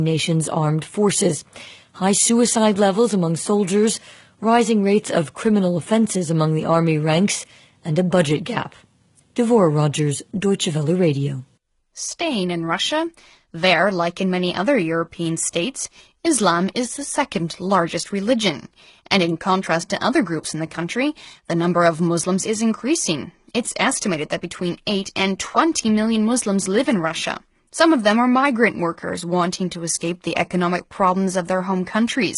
Hörproben und Mitschnitte von DRM als 56-kBit/s (oder mehr)-MP3-Dateien: Alle Beispiele sind mit dem hier beschriebenen Empfänger entstanden. Die Bandbreite des Sendesignals betrug immer 10 kHz.
drm-sprache-englisch-17kb.mp3